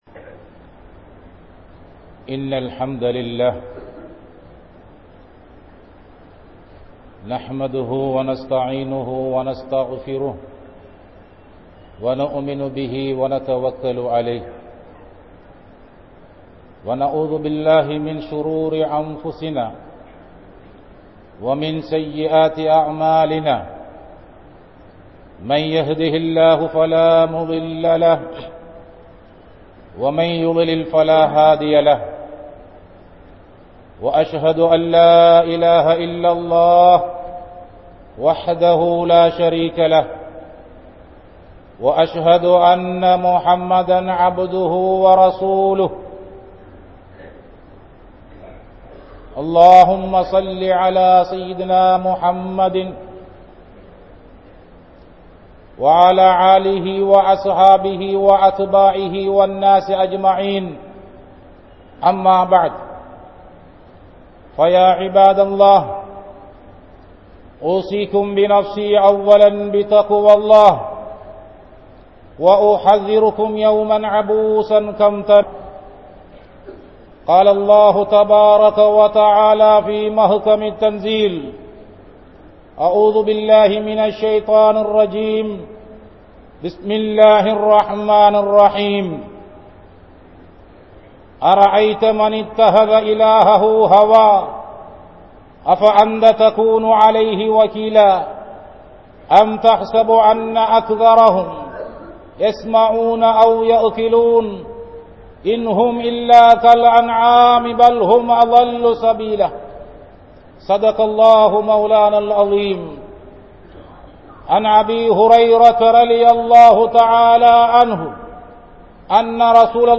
Manitha Vaalvin Unami Nilai (மனித வாழ்வின் உண்மை நிலை) | Audio Bayans | All Ceylon Muslim Youth Community | Addalaichenai
Grand Jumua Masjith